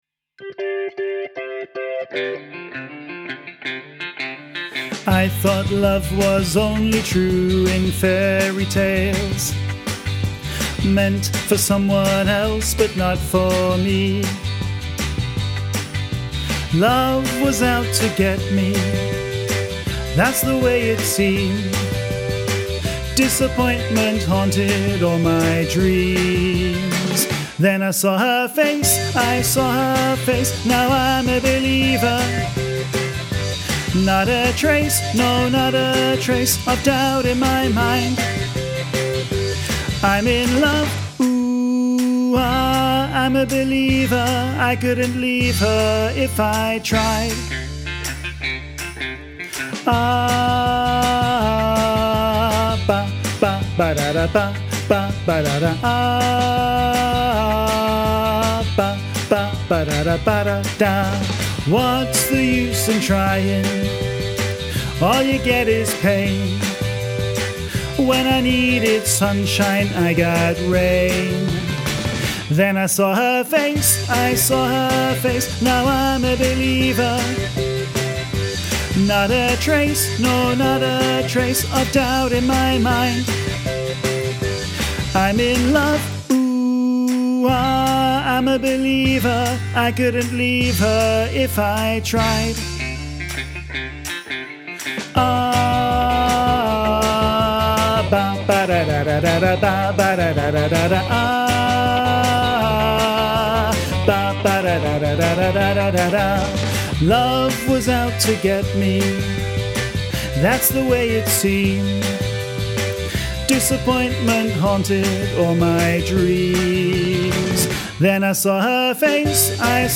Training Tracks for I'm a Believer
im-a-believer-bass.mp3